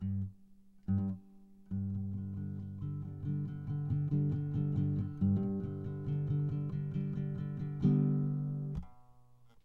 GUITAR2.WAV